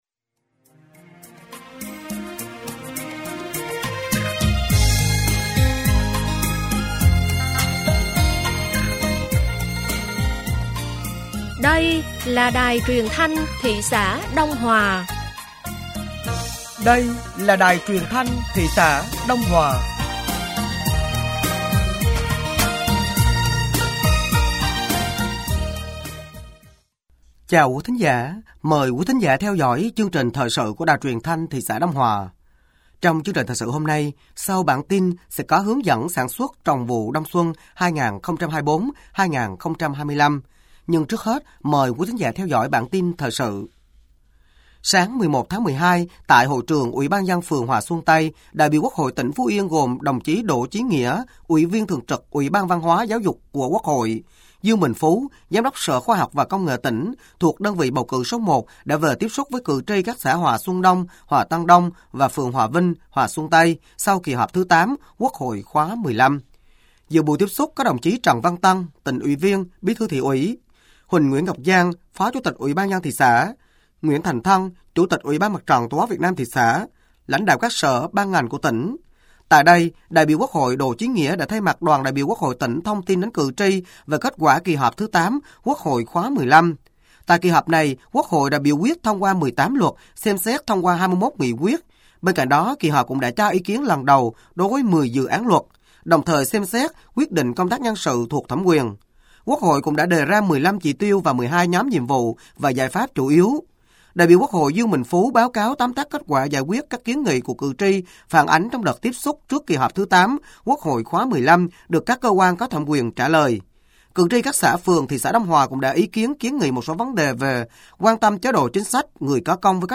Thời sự tối ngày 11 và sáng ngày 12 tháng 12 năm 2024